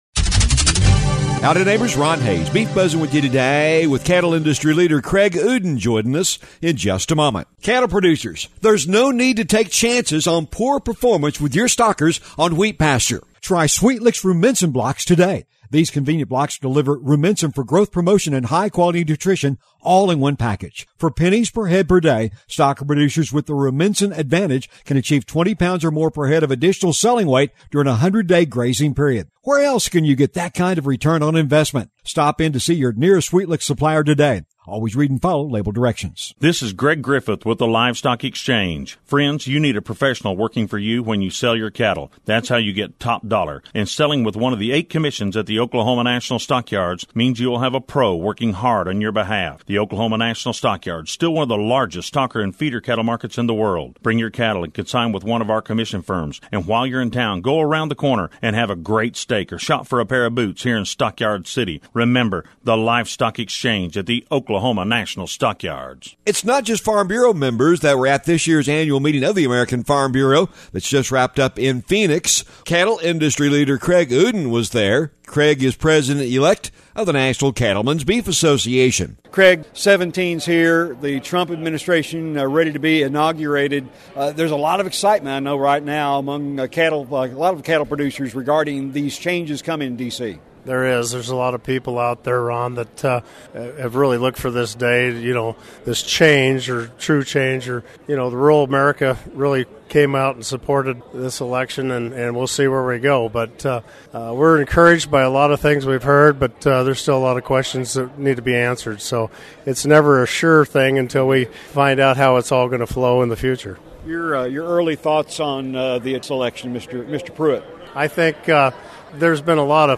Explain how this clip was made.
The Beef Buzz is a regular feature heard on radio stations around the region on the Radio Oklahoma Network and is a regular audio feature found on this website as well.